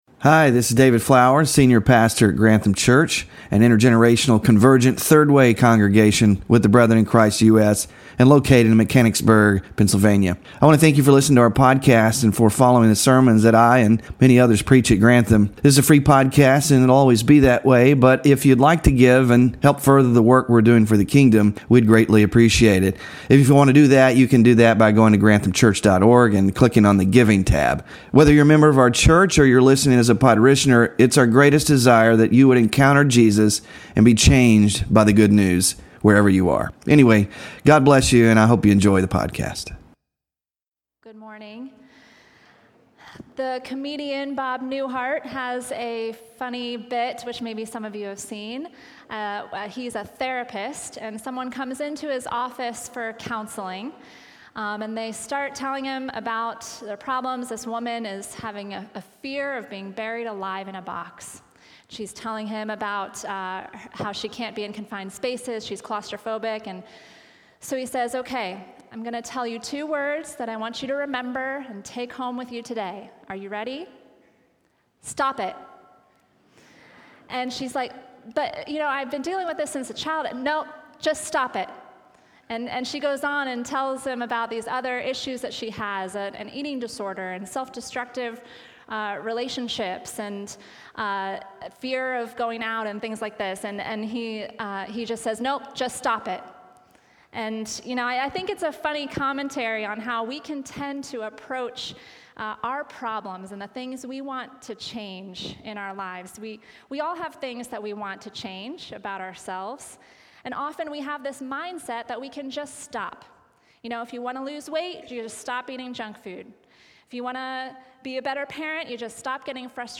GLORY OF GOD WK4 SERMON SLIDES SMALL GROUP DISCUSSION QUESTIONS (5-26-24)